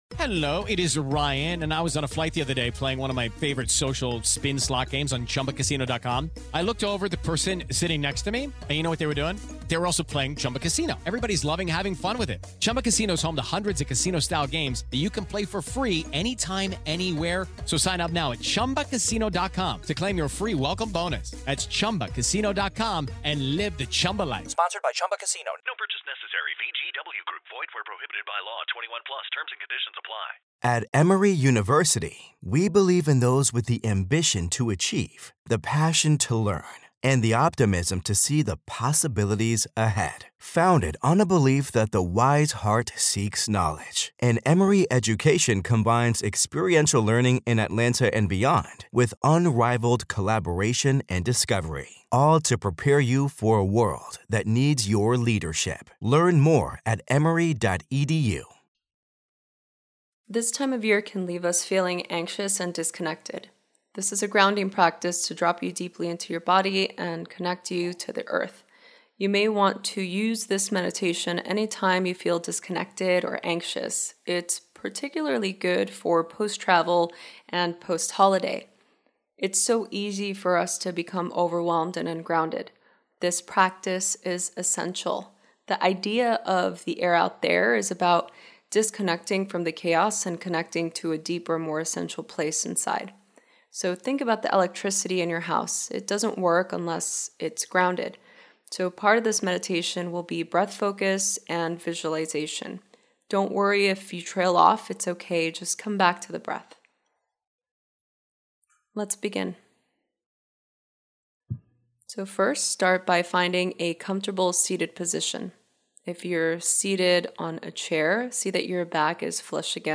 Episode 117| MEDITATION| The Air Out There *repost